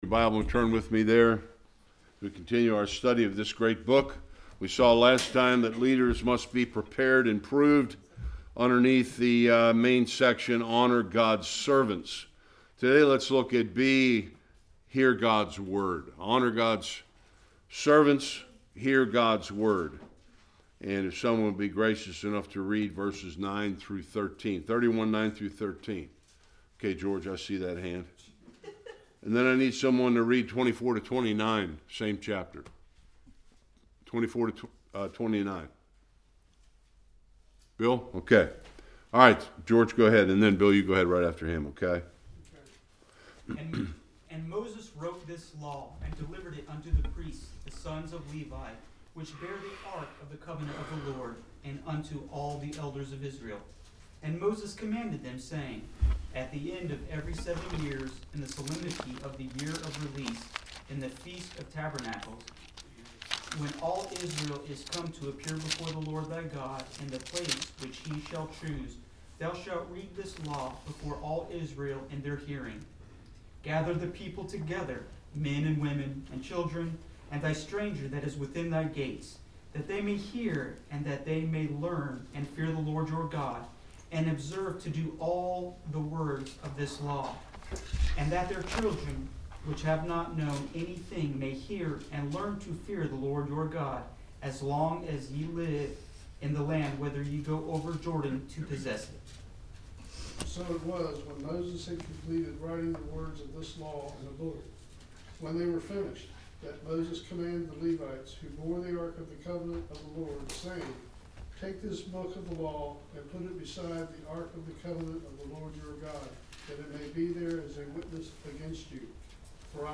24-29 Service Type: Sunday School God reveals through Moses the disturbing future that awaits Israel.